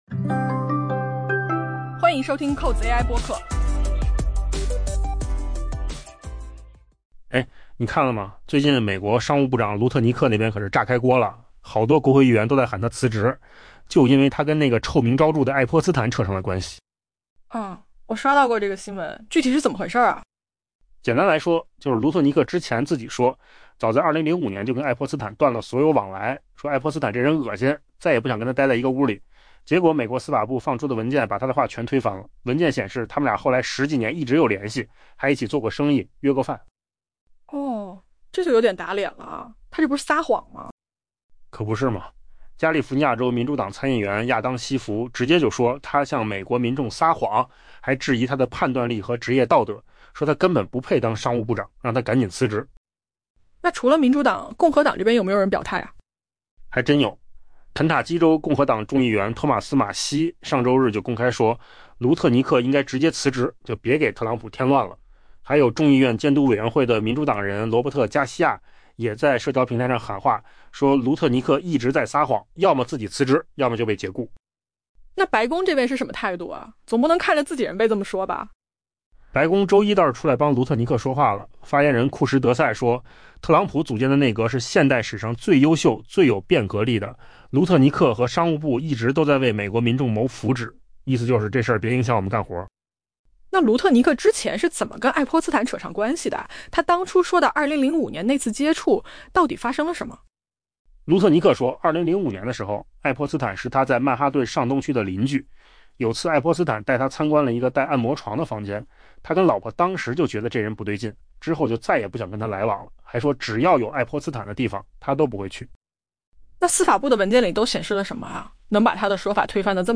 AI播客：换个方式听新闻 下载mp3 音频由扣子空间生成 越来越多的美国国会议员正呼吁商务部长霍华德・卢特尼克（Howard Lutnick）辞去特朗普内阁职务，原因是他与已定罪的性犯罪者杰弗里・爱泼斯坦（Jeffrey Epstein）存在关联。